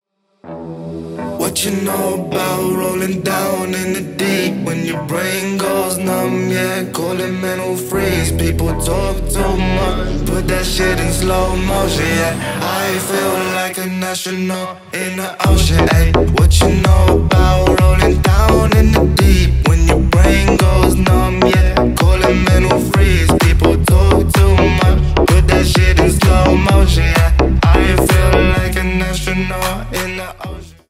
Рэп и Хип Хоп
клубные # громкие